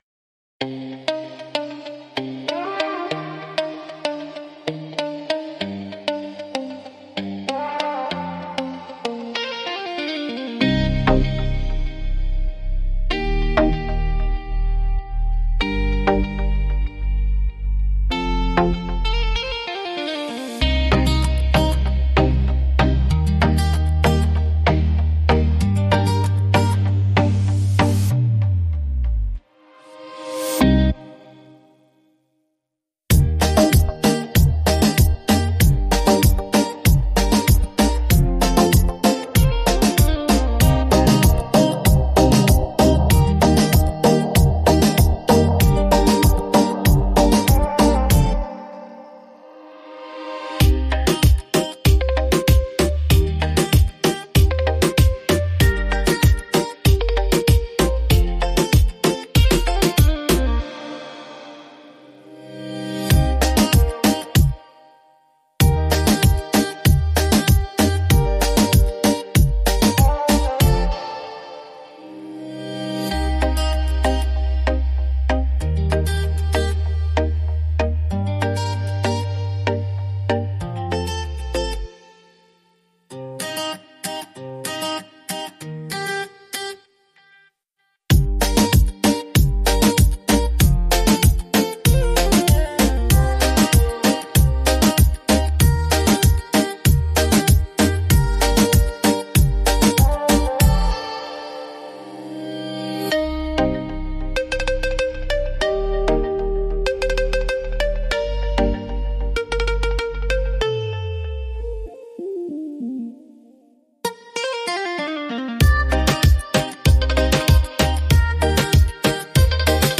Beat Reggaeton Instrumental
Acapella e Cori Reggaeton Inclusi
Cm